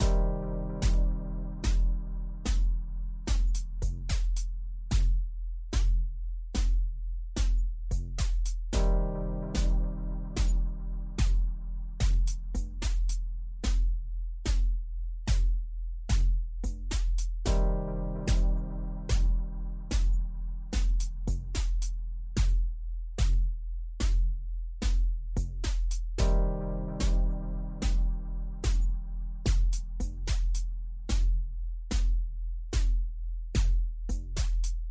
小号 (3)
描述：通过将kick + snare声音导入SMAF WSD，然后使用VOX ADPCM格式将原始数据导入Audacity，创建一个假的小鼓圈音。
标签： 嘈杂 假的 小鼓 活泼
声道立体声